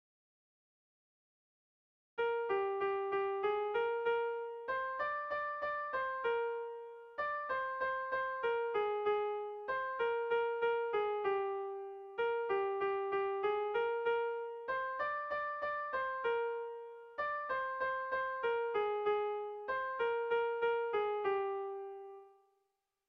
Kontakizunezkoa
Zortziko txikia (hg) / Lau puntuko txikia (ip)
ABAB